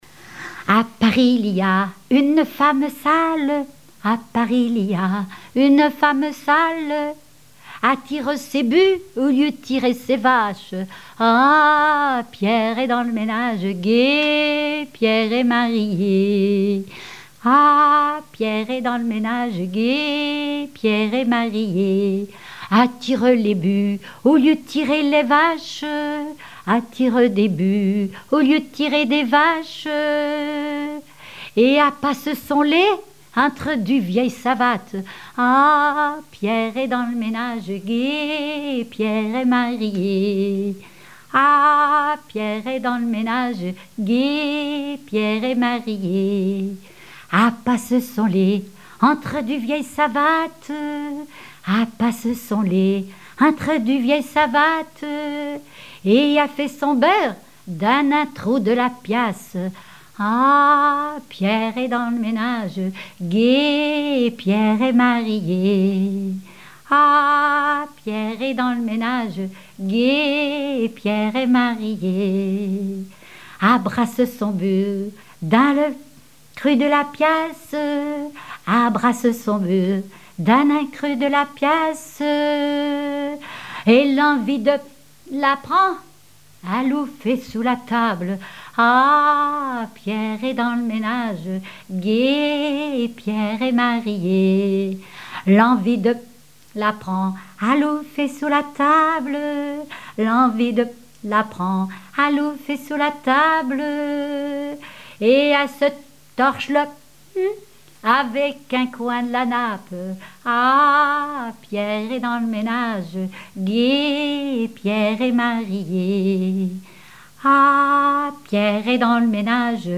Mémoires et Patrimoines vivants - RaddO est une base de données d'archives iconographiques et sonores.
Genre laisse
chansons populaires et traditionnelles
Pièce musicale inédite